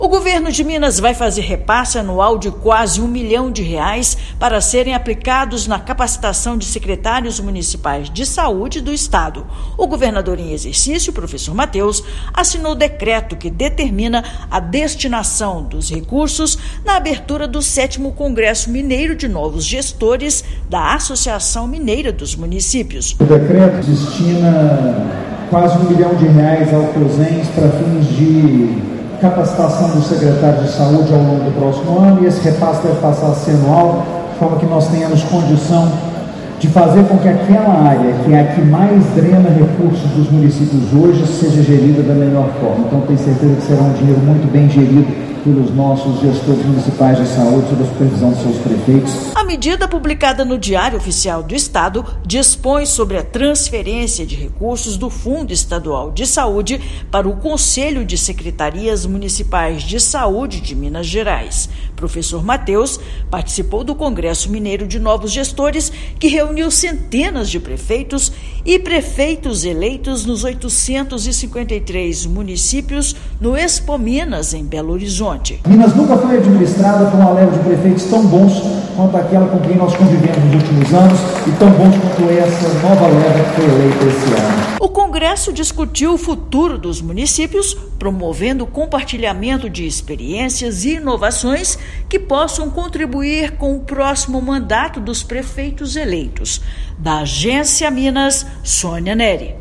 Repasse foi anunciado durante congresso promovido pela Associação Mineira dos Municípios. Ouça matéria de rádio.